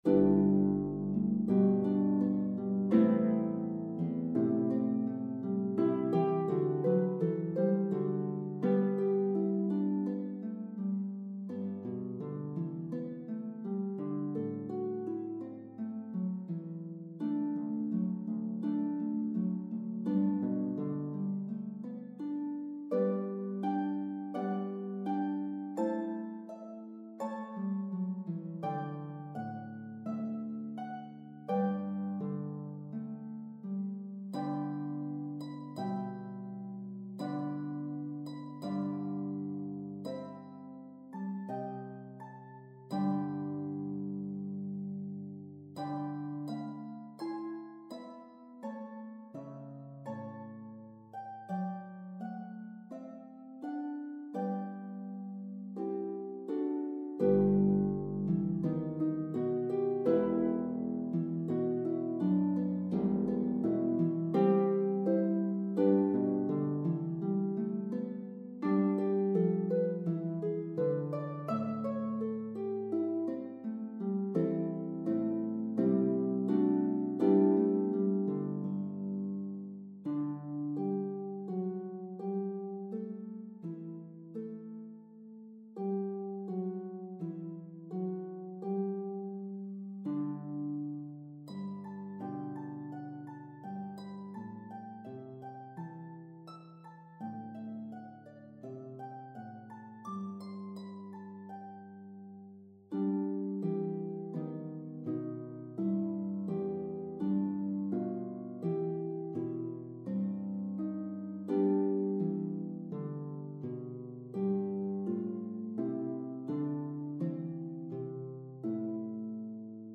for mixed harp ensemble
Practice tracks
Harp 2